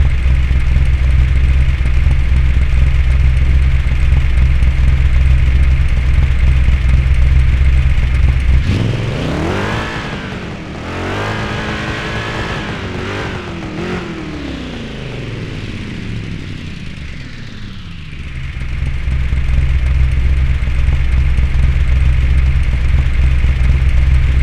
Index of /server/sound/vehicles/sgmcars/buggy
idle.wav